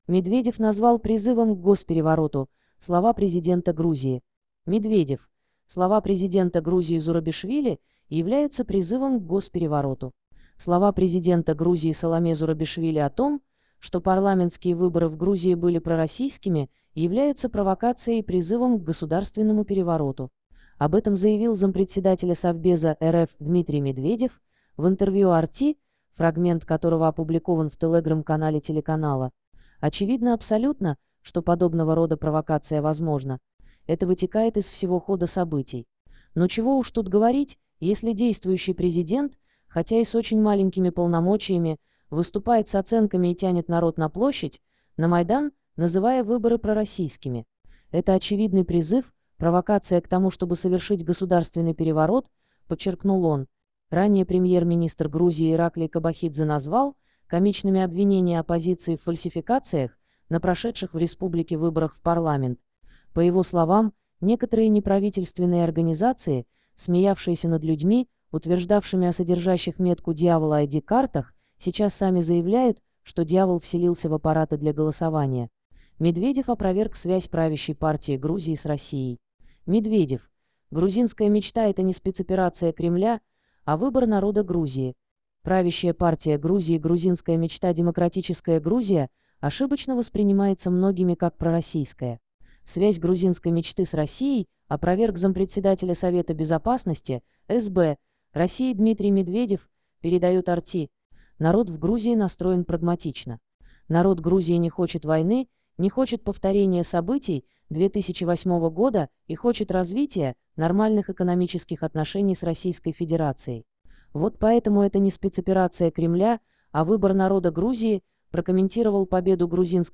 Слова президента Грузии Саломе Зурабишвили о том, что парламентские выборы в Грузии были пророссийскими, являются провокацией и призывом к государственному перевороту. Об этом заявил зампредседателя Совбеза РФ Дмитрий Медведев в интервью RT, фрагмент которого опубликован в Telegram-канале телеканала.